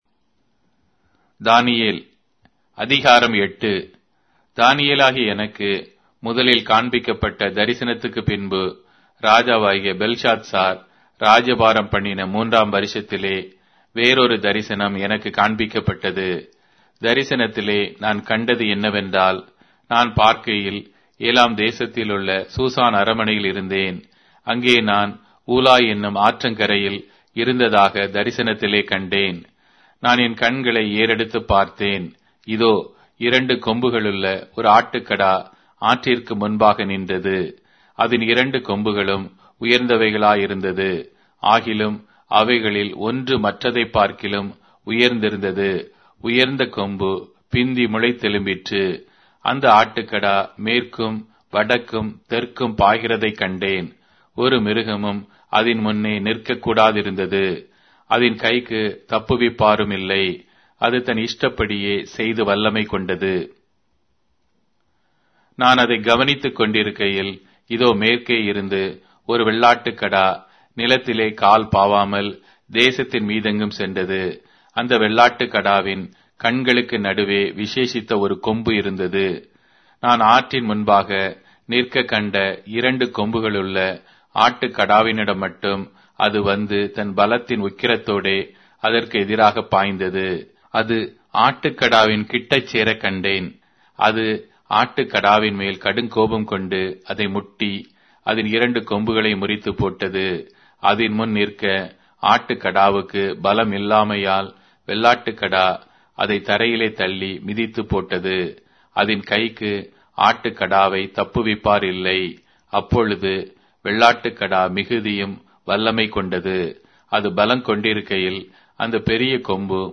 Tamil Audio Bible - Daniel 12 in Esv bible version